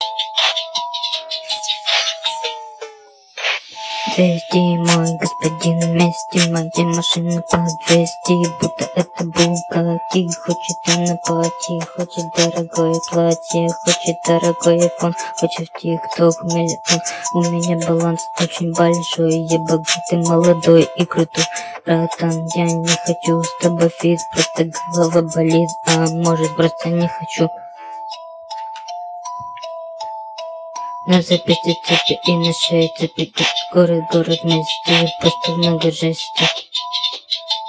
речитатив